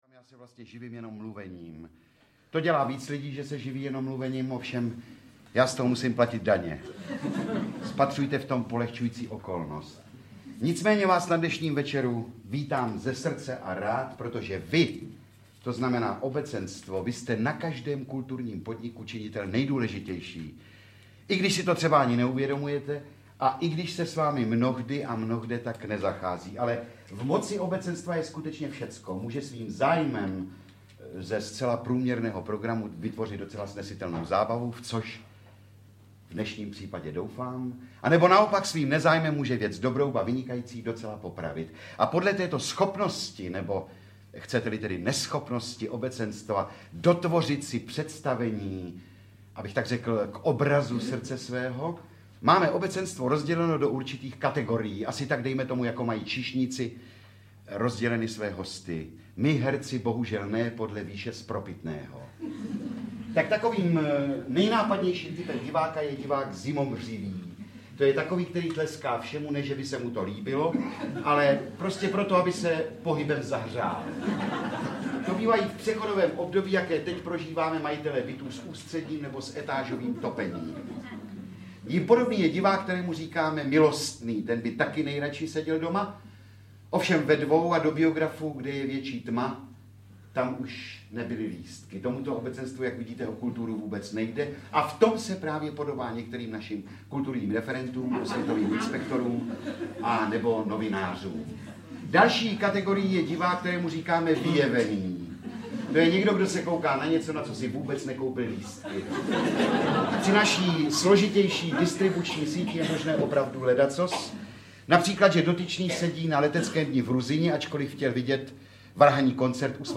Večer s Bohumilem Bezouškou audiokniha
Ukázka z knihy
Teprve po jeho odchodu z našich řad se jeho slovapřipomněla znova - a tak vznikl můj Violový Legrecitál, těšící se tak velkému zájmu herecké i neherecké veřejnosti. Ve snaze umožnit i těm, kteří to mají do Violy z ruky, alespoň částečnou účast na tomto večeru i pro ty, kdož si chtějí připomenout jeho atmosféru, pořídili jsme ze zvukového záznamu Legrecitálu jakýsi výběr.
• InterpretBohumil Bezouška